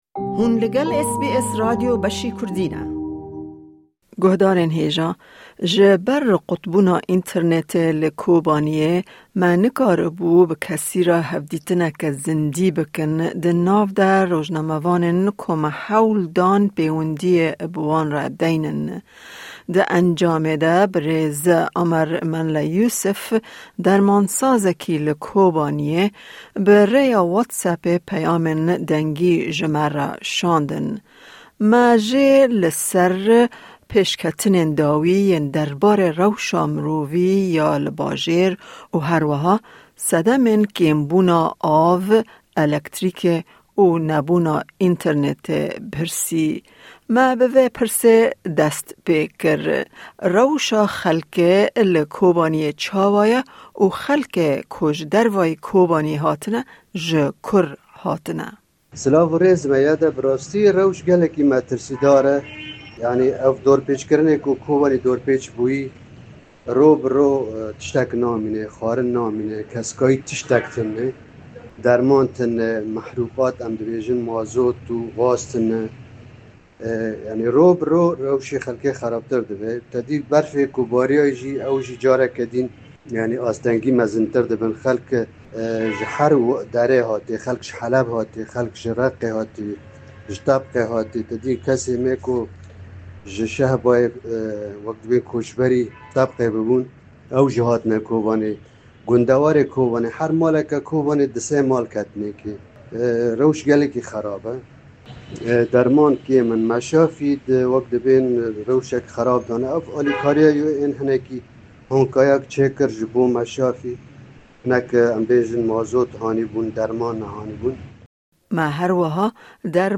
Due to the internet blackout in Kobani, we were unable to reach anyone for a live interview, including the journalists we attempted to contact.